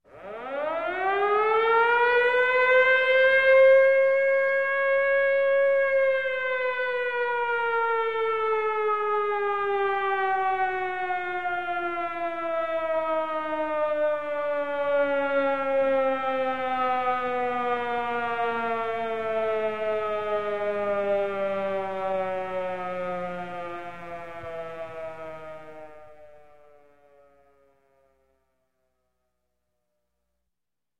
Звуки сирены
В городе раздается сирена воздушной тревоги